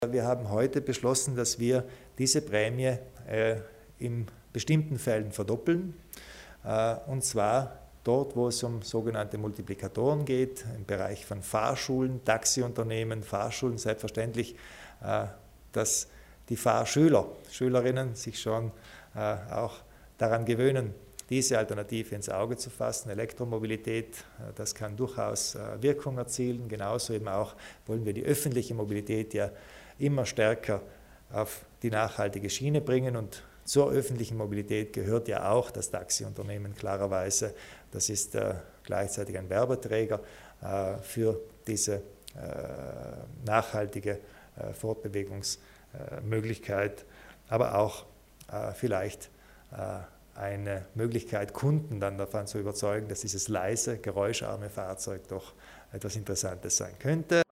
Landeshauptmann Kompatscher zu den Förderungen für die E-Mobilität